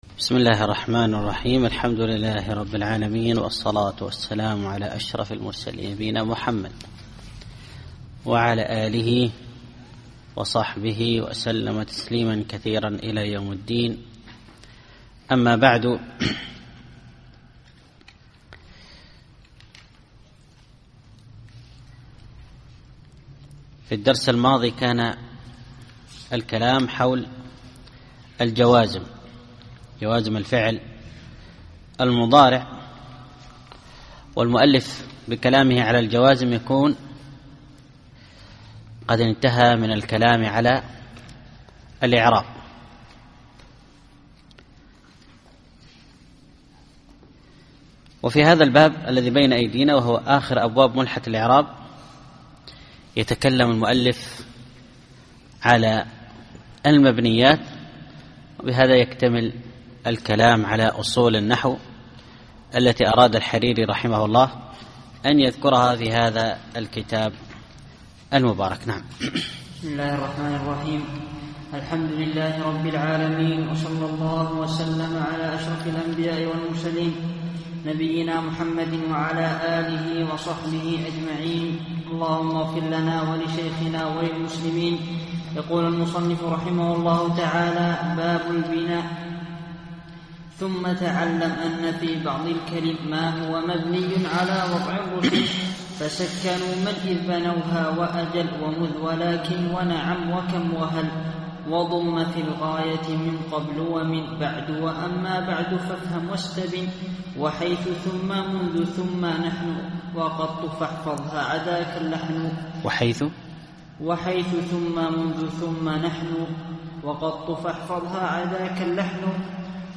الدرس الثاني والثلاثون والأخير الأبيات 360-379